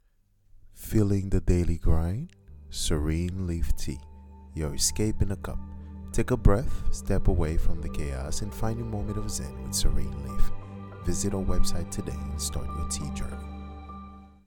Online Ad: Serene leaf tea: warm, inviting, soothing, calm, serene, sophisticated, yet approachable.